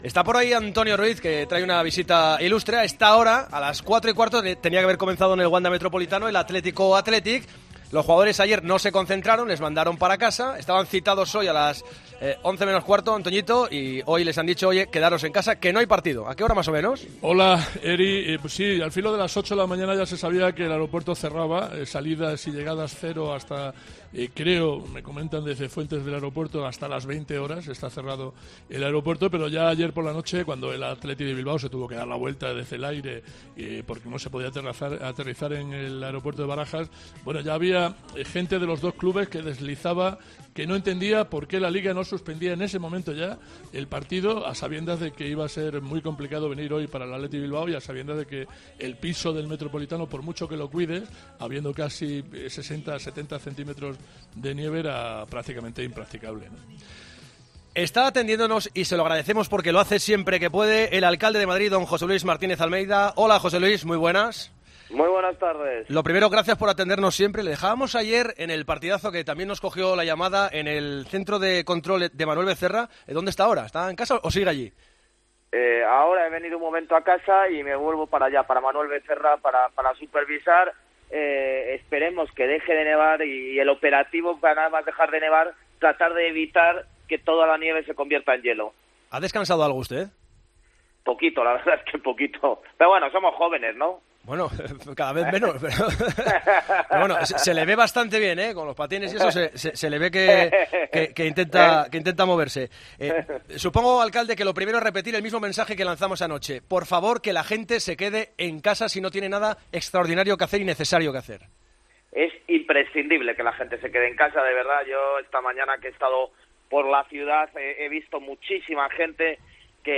Entrevista en Tiempo de Juego
El alcalde de Madrid, José Luis Martínez Almeida, ha pasado este sábado por los micrófonos de Tiempo de Juego tras el paso del temporal Filomena por la capital que ha dejado más de 30 centímetros de nieve en muchos lugares de la ciudad.